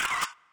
Perc [Famous].wav